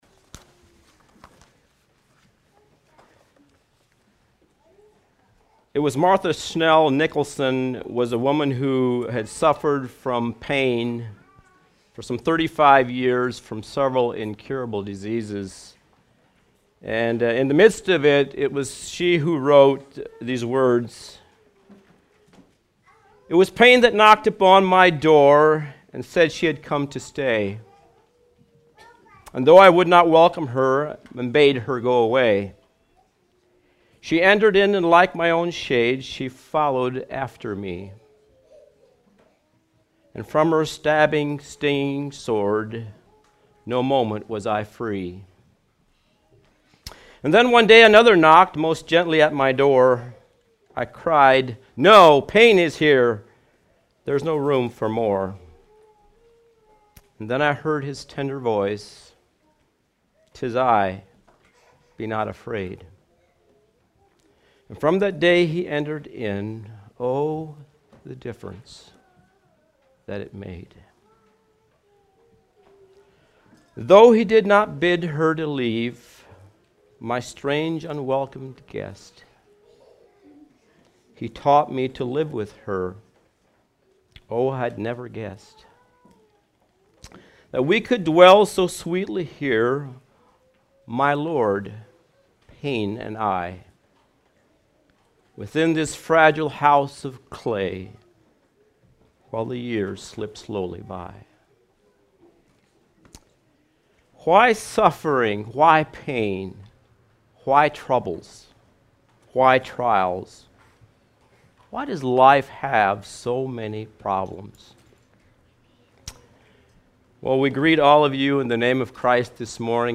Sermons The Epistle of James